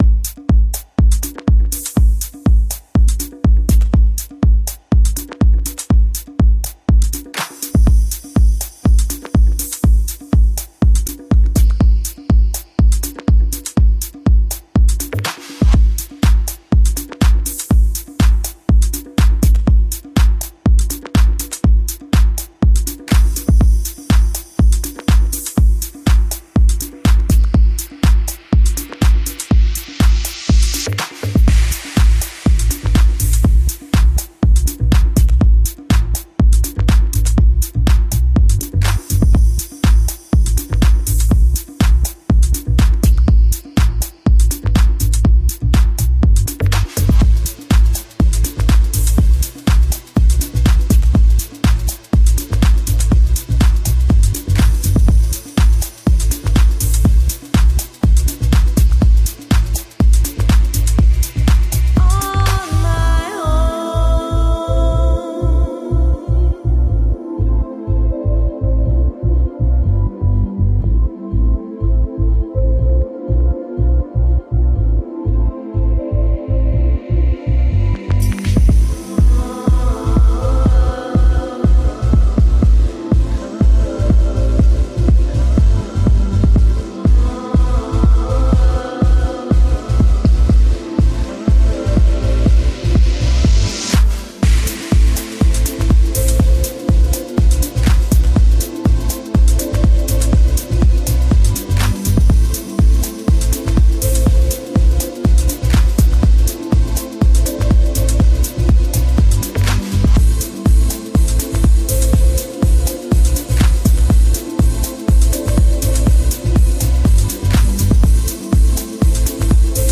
Melancholy, loneliness, desire.